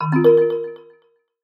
HTC Desire Bildirim Sesleri